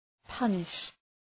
Προφορά
{‘pʌnıʃ}